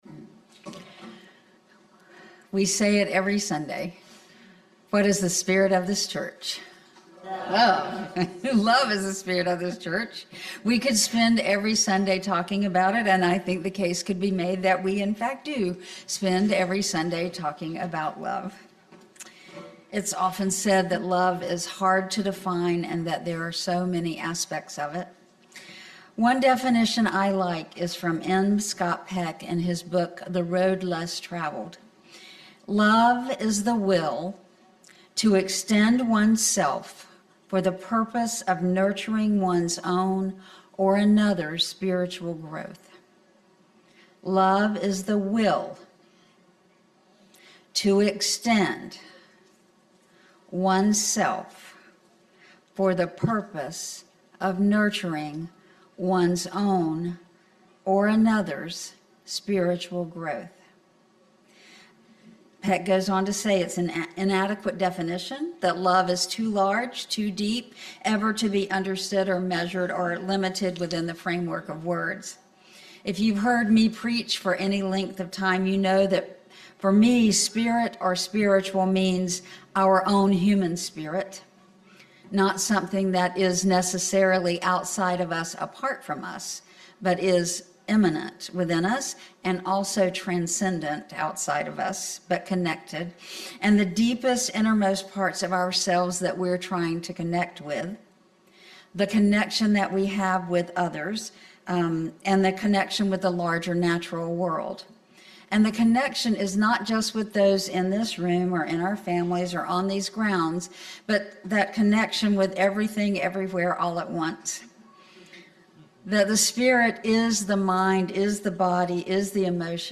This sermon explores love as the central spirit of the Unitarian Universalist faith, defining it not as a fleeting emotion but as an intentional action and a commitment to spiritual growth.